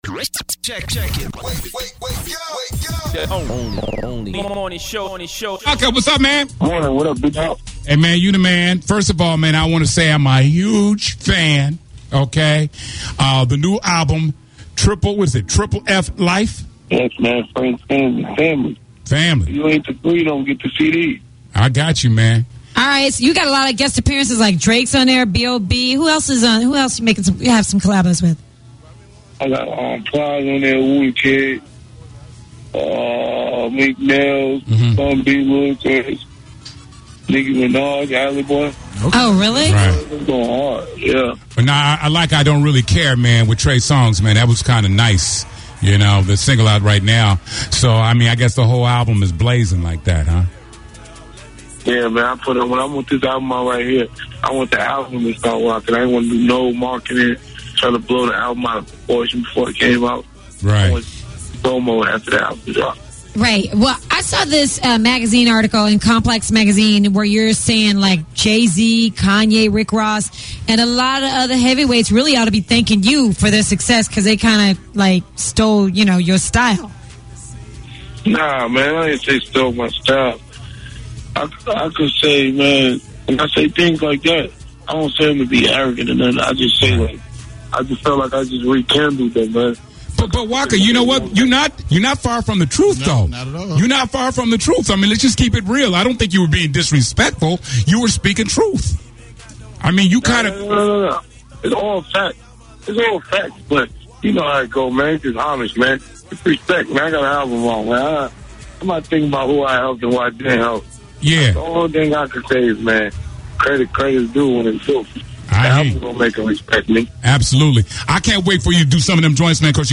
The Russ Parr Morning Show interviews Waka Flocka Flame, Waka Flocka Flame
This morning we asked Waka, one of the artist to perform at Radio One Fest that very question. Take a listen to the interview below and hear his response.